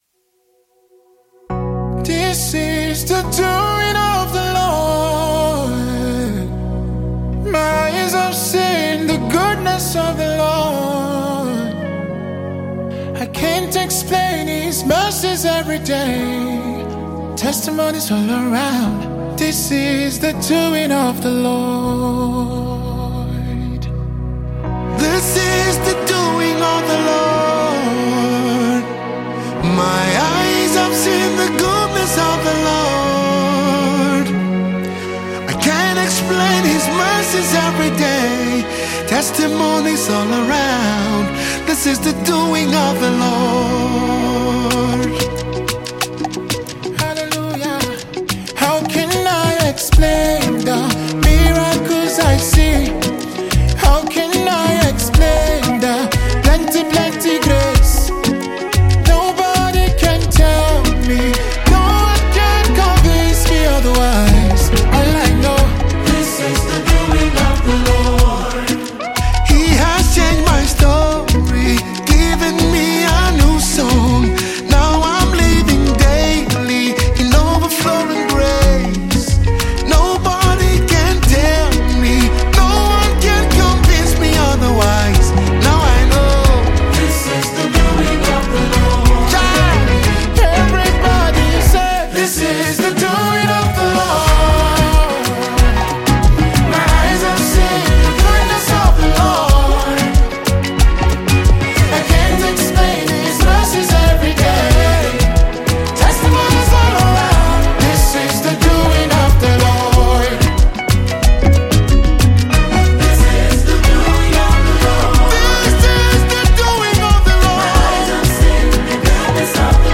African Gospel Music
powerful gospel song
soulful voice